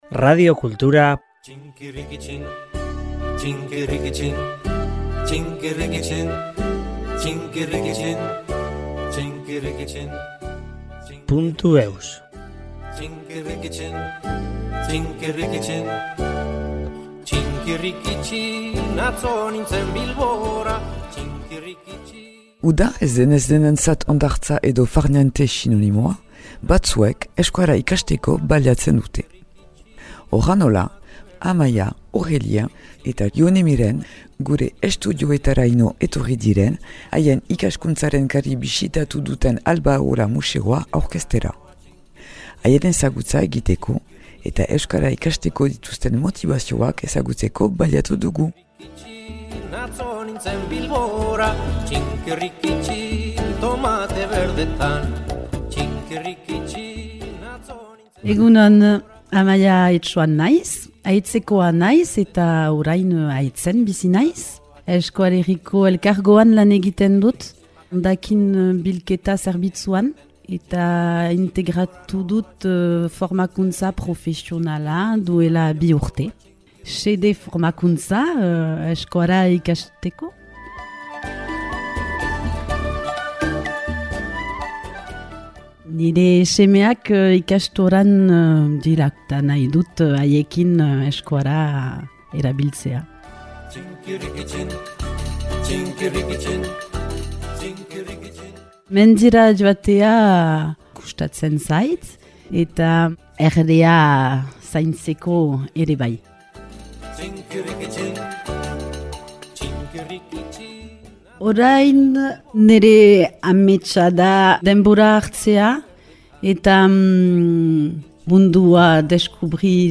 Uda ez denez denentzat hondartza edo « farniente » sinonimoa, batzuek euskara ikasteko baliatzen dute ! Aek-ko talde bat jin da gure estudioa bisitatzera eta nola ez, haien ezagutza egiteko eta euskara ikasteko dituzten motibazioak ezagutzeko baliatu dugu !